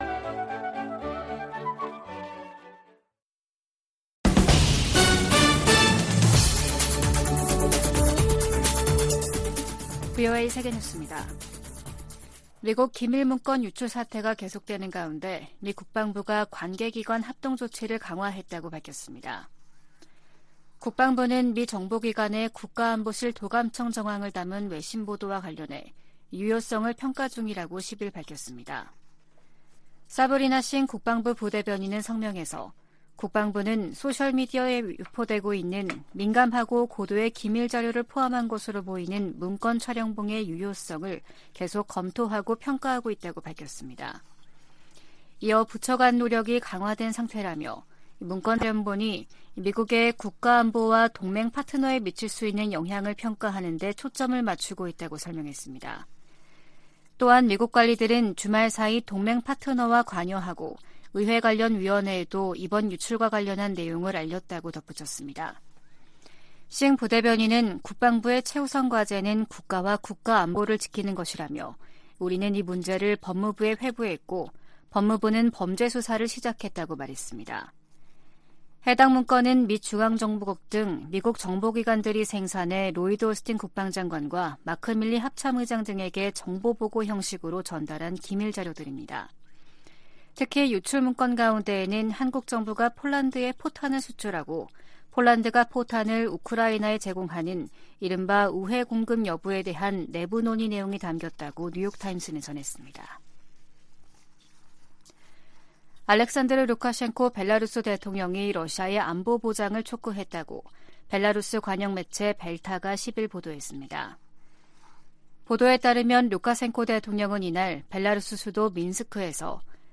VOA 한국어 아침 뉴스 프로그램 '워싱턴 뉴스 광장' 2023년 4월 11일 방송입니다. 한국 대통령실은 미국 정보기관의 국가안보실 감청정황 보도에 관해 "필요할 경우 미국에 합당한 조치를 요청할 것"이라고 밝혔습니다. 미국 의원들이 윤석열 한국 대통령 의회 연설 초청을 환영한다고 밝혔습니다. 북한이 '수중핵어뢰'로 알려진 핵무인 수중 공격정 '해일'의 수중 폭파시험을 또 다시 진행했다고 밝혔습니다.